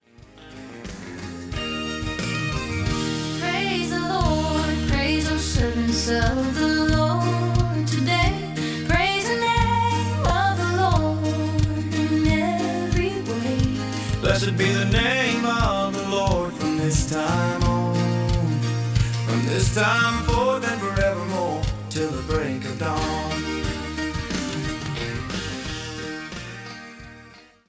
authentic Country worship
the authentic sound of modern Country worship
From intimate acoustic moments to full-band celebrations